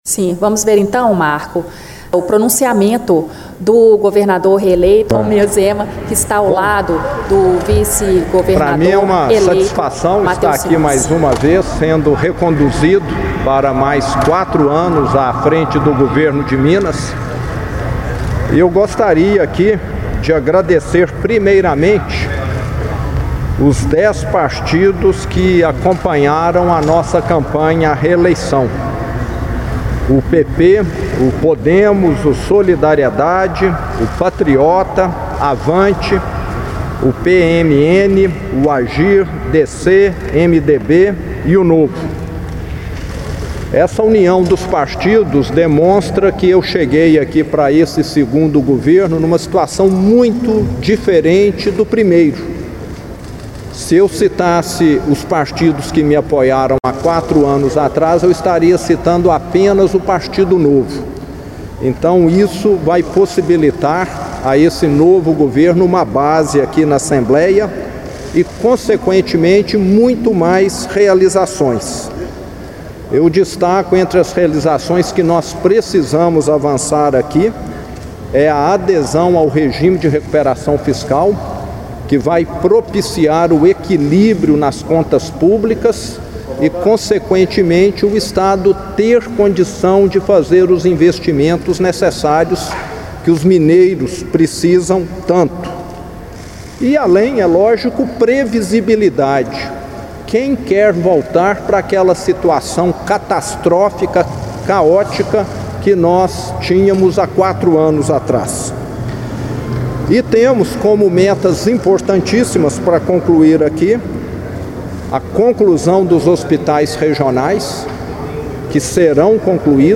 Discursos e Palestras
O governador de Minas fez o pronunciamento minutos antes da solenidade de posse em reunião especial no Plenário da Assembleia Legislativa de Minas. A adesão de Minas ao regime de recuperação fiscal, obras do metrô de Belo Horizonte e em hospitais foram apontadas como prioridade.